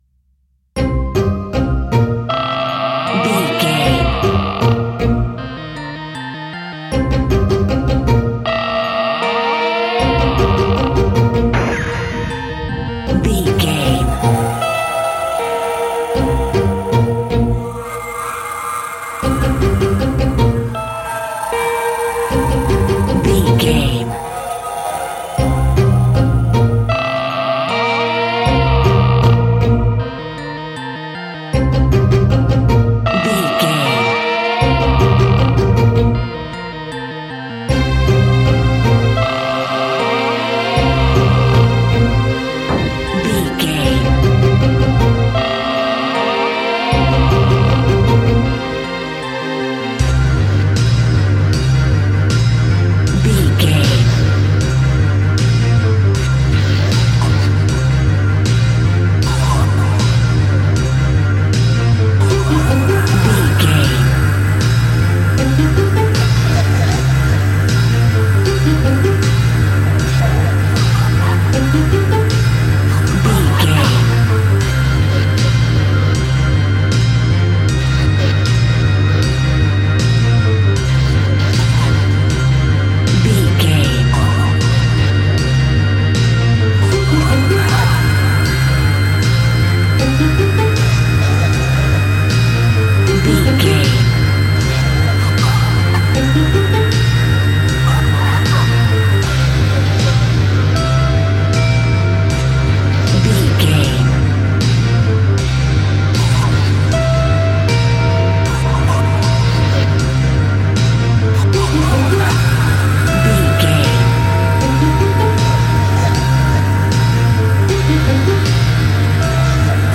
Aeolian/Minor
ominous
eerie
strings
synthesiser
percussion
electric guitar
drums
electric organ
harp
horror music